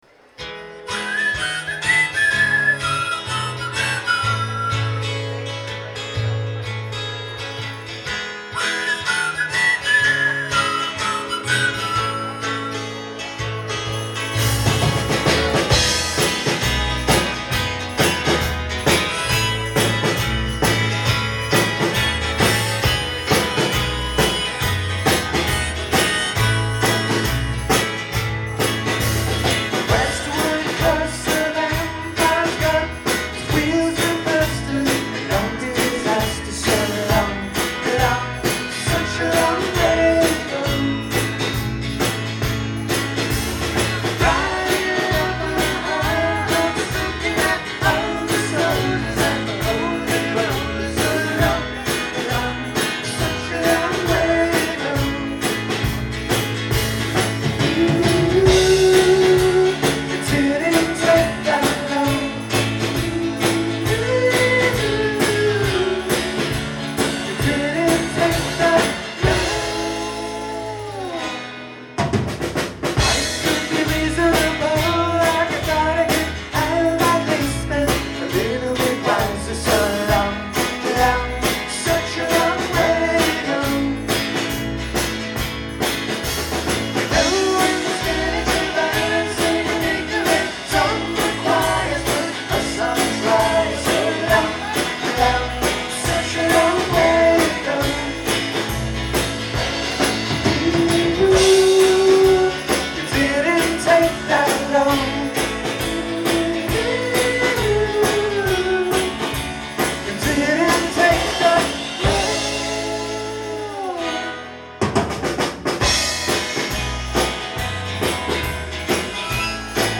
Live at Great Scott